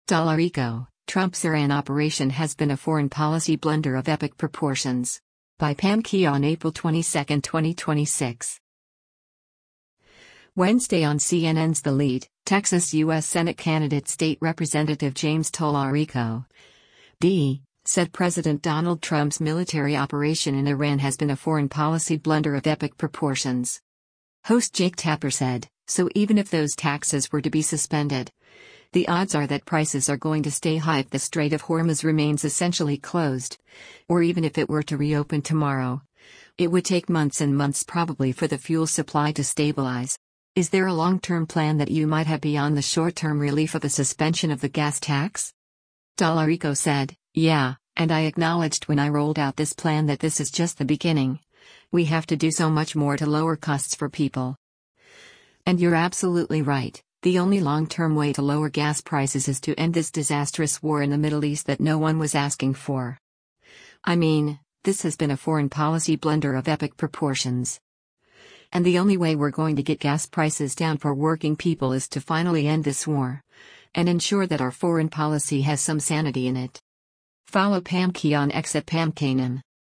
Wednesday on CNN’s “The Lead,” Texas U.S. Senate candidate State Rep. James Talarico (D) said President Donald Trump’s military operation in Iran “has been a foreign policy blunder of epic proportions.”